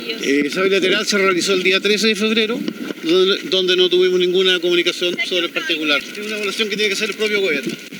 Por su parte, el designado ministro del Interior, Claudio Alvarado, también explicitó estas críticas. En conversación con Mesa Central de T13, aseguró que el equipo no contaba con antecedentes sobre la materia hasta que el tema comenzó a aparecer en la prensa.